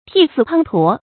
發音讀音
成語簡拼 tspt 成語注音 ㄊㄧˋ ㄙㄧˋ ㄆㄤ ㄊㄨㄛˊ 成語拼音 tì sì pāng tuó 發音讀音 常用程度 常用成語 感情色彩 貶義成語 成語用法 聯合式；作謂語、狀語；含貶義，用于書面語 成語結構 聯合式成語 產生年代 古代成語 成語正音 滂，不能讀作“pánɡ”。